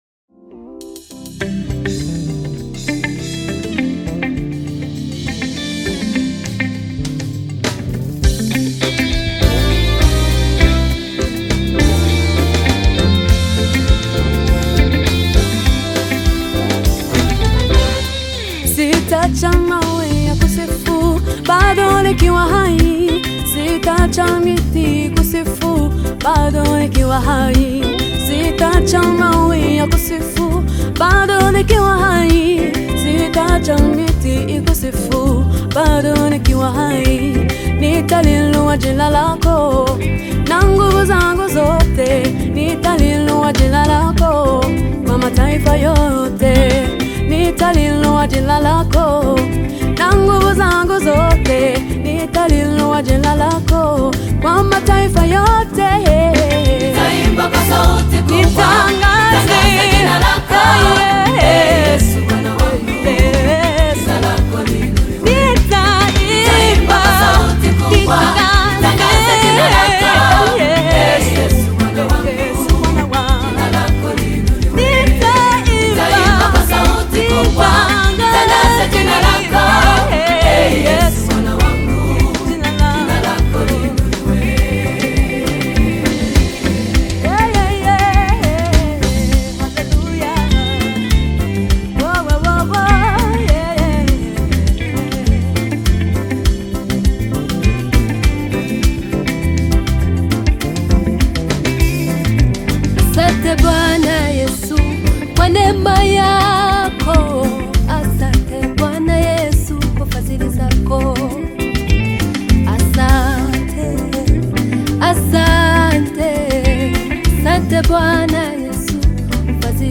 Rwandans gospel singers and choir
worship gospel song